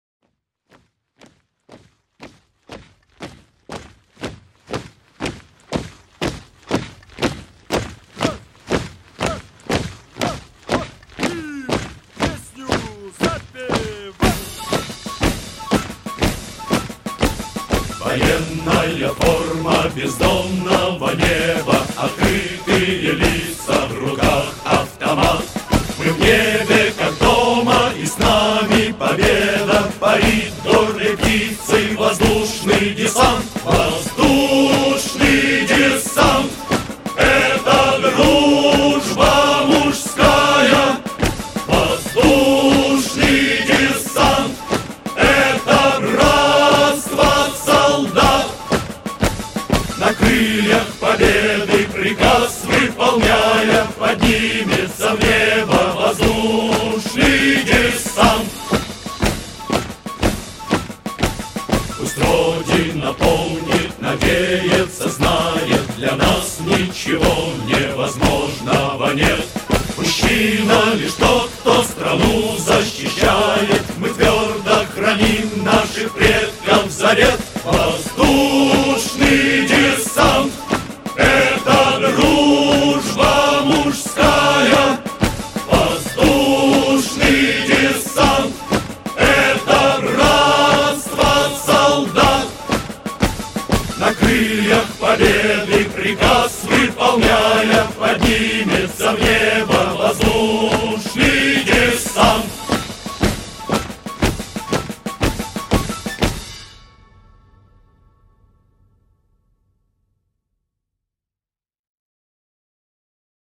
Парадный марш